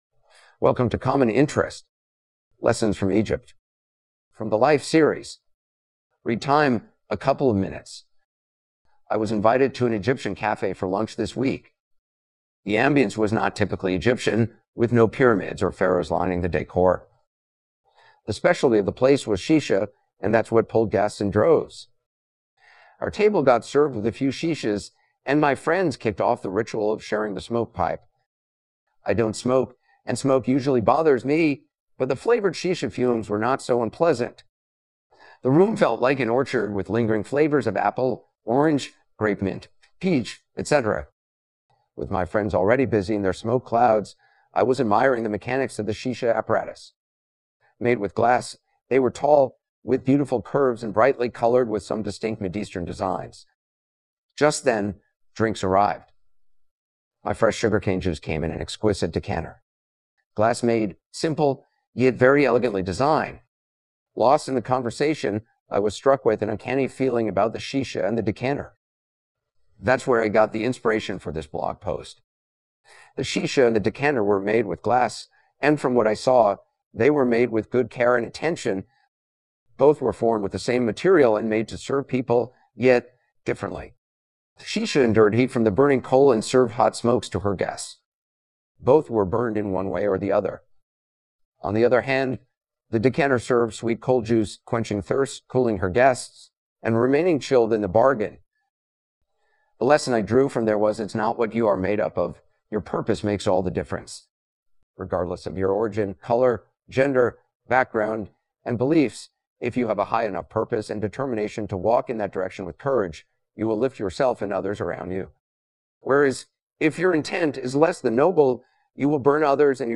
For those who prefer listening vs reading, I have an audio version of the blog.